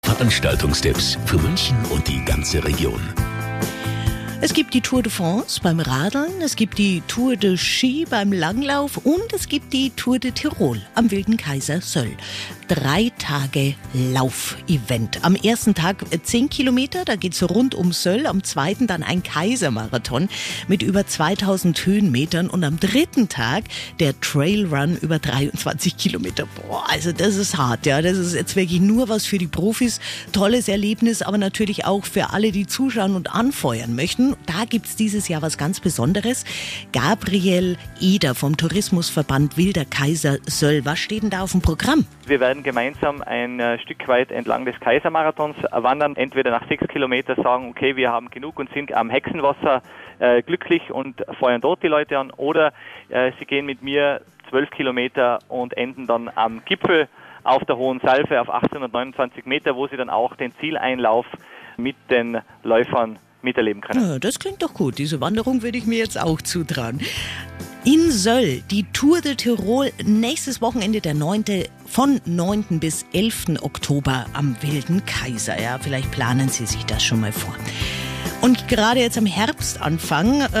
We are very busy at the moment with all preparations for next week, sometimes also by giving interviews ...